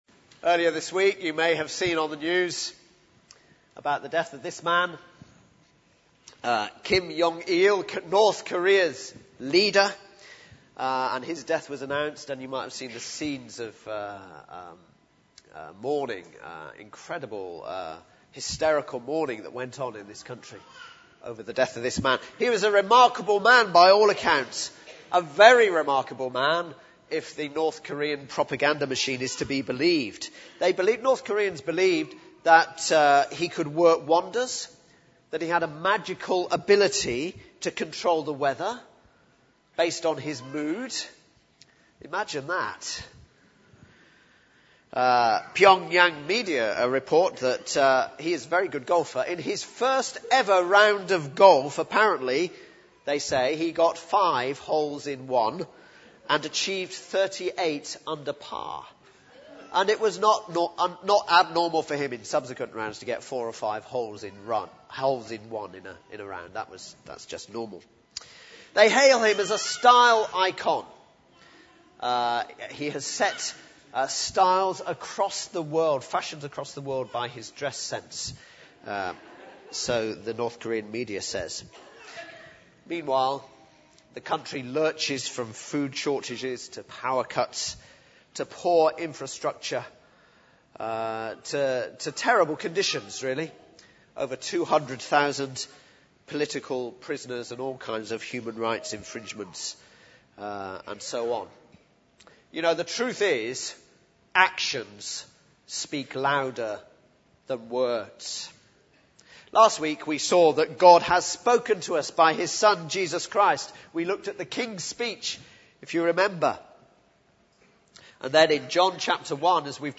speaks on Christmas Day 2011.